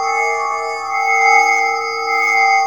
A#4 NEURO05L.wav